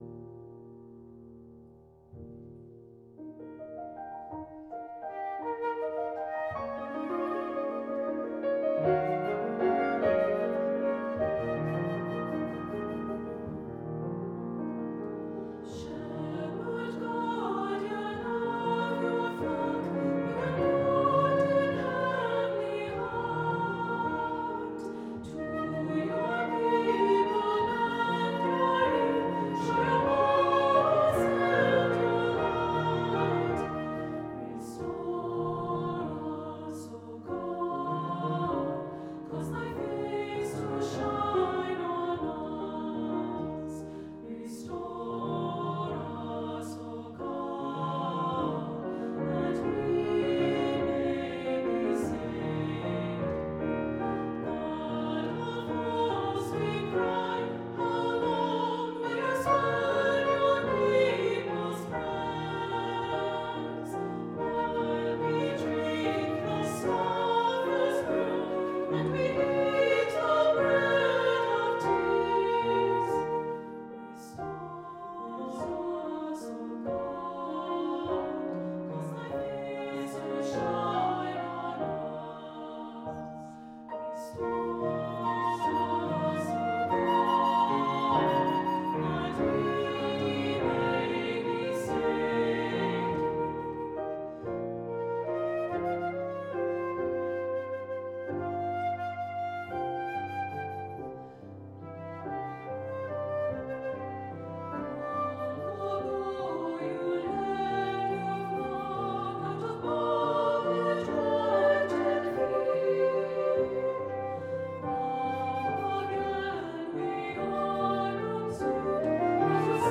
Voicing: Two-part equal; Two-part mixed; Assembly